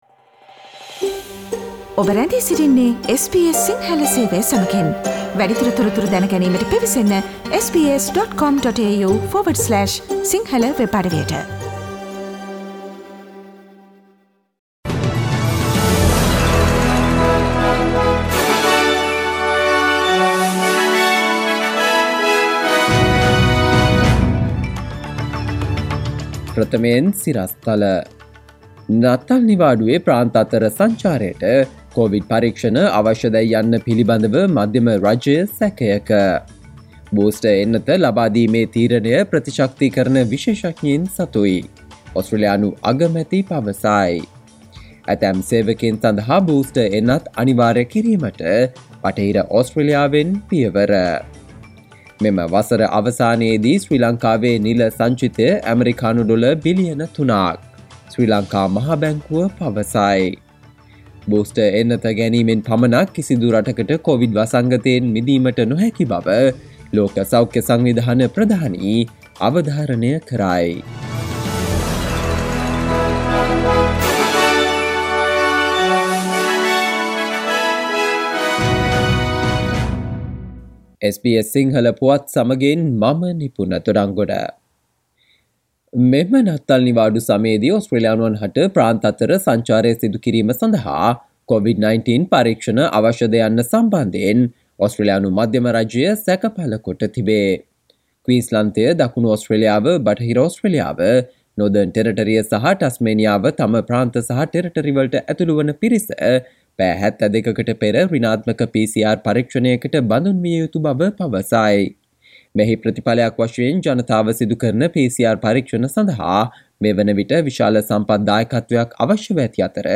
සවන්දෙන්න 2021 දෙසැම්බර් 23 වන බ්‍රහස්පතින්දා SBS සිංහල ගුවන්විදුලියේ ප්‍රවෘත්ති ප්‍රකාශයට...